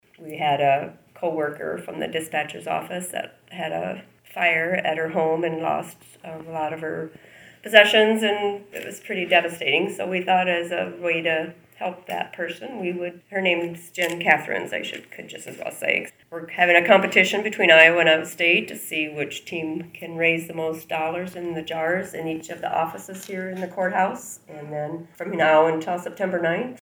Dakota City, IA -The Humboldt County Courthouse is holding a fundraiser for a co-worker effected by a fire. Humboldt County Treasurer Jana Bratland has some details.